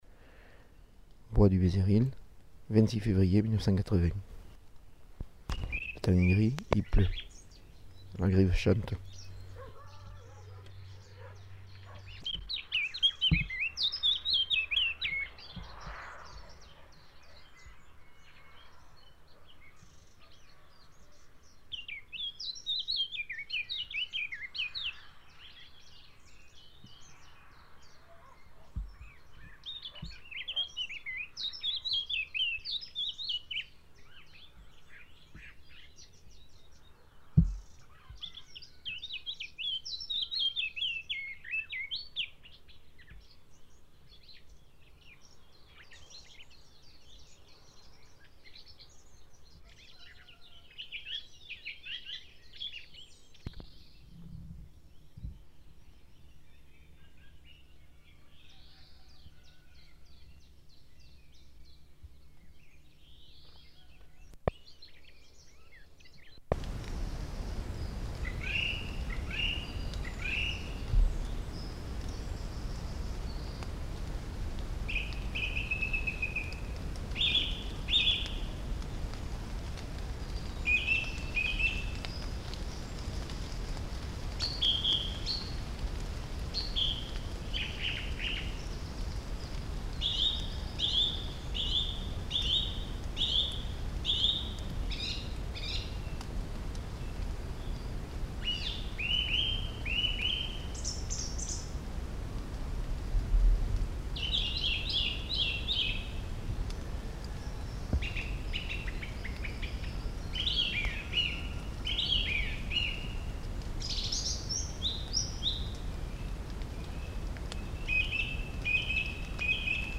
Enregistrement de chants d'oiseaux et de bruits de la nature
Type de son : mono
Qualité technique : moyen
Enregistrement de chants d'oiseaux dont celui de la grive et de bruits de la nature dans le bois de Bézéril.
Lieu : Bézéril (bois)